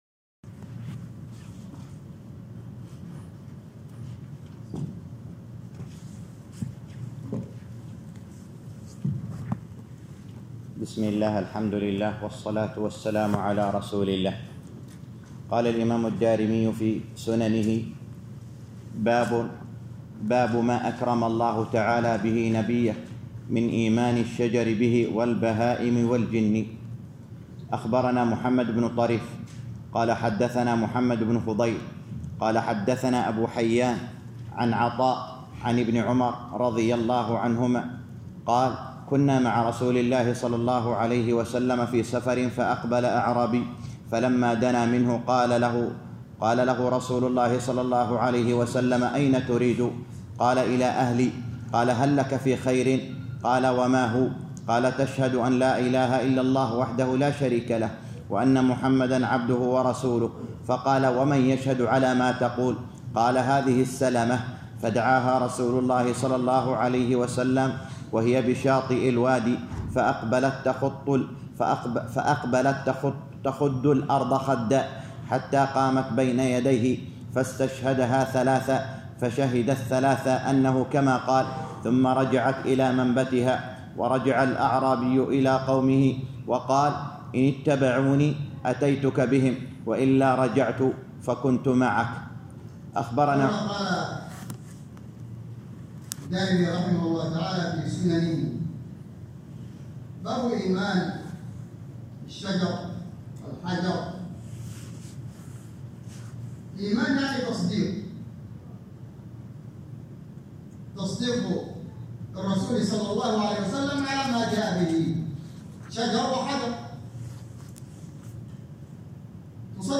الدرس السادس - شرح سنن الدارمي الباب الرابع _ 6